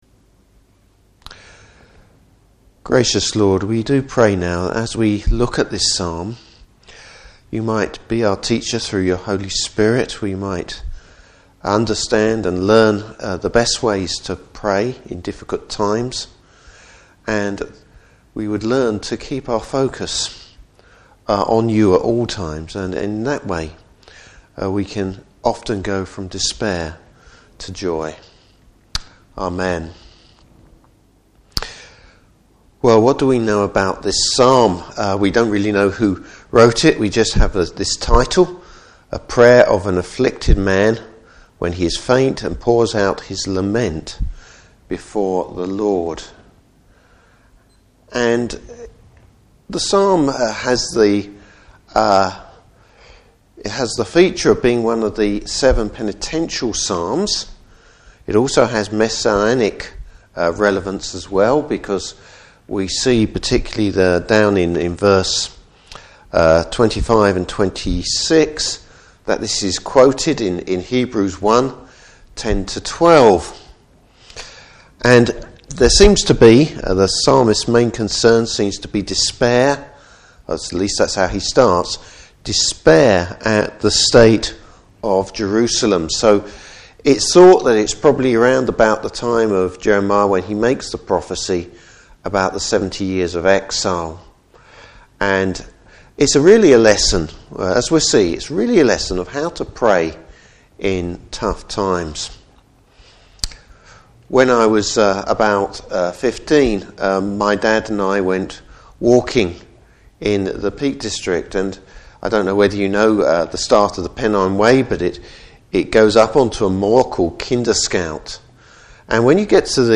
Service Type: Evening Service Bible Text: Psalm 102.